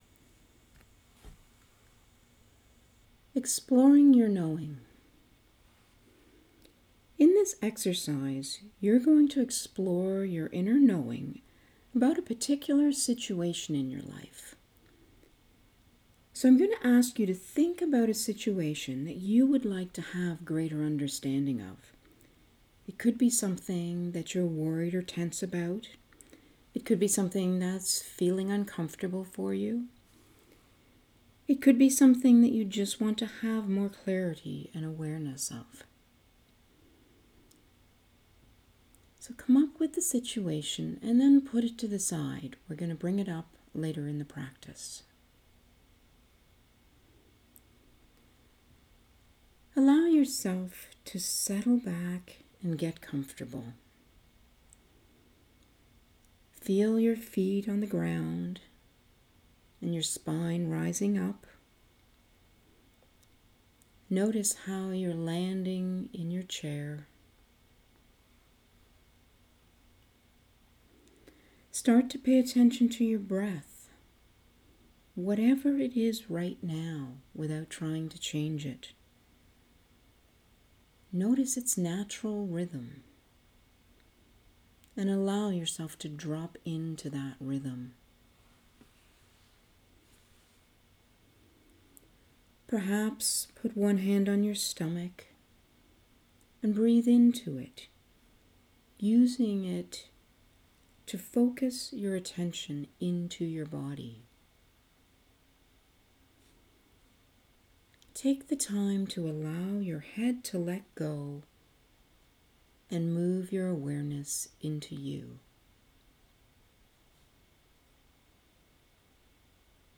Focus: Guided Visualization